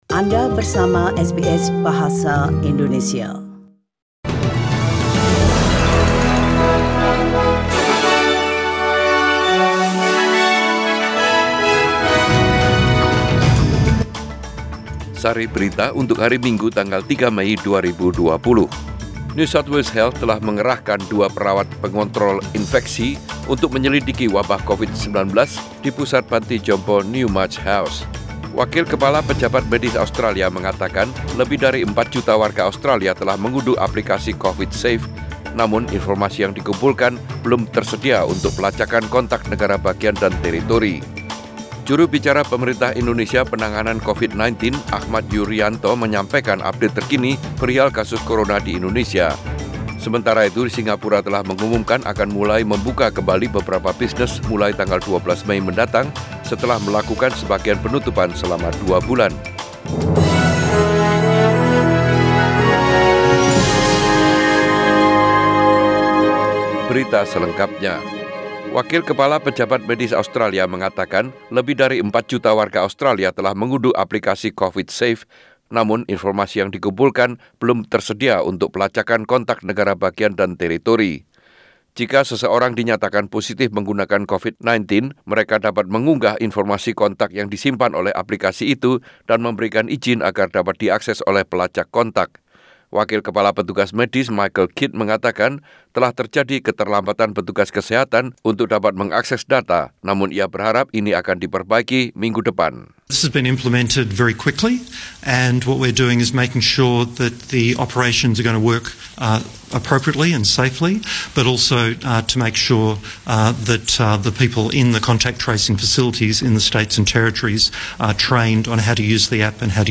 SBS Radio News in Bahasa Indonesia - 3 May 2020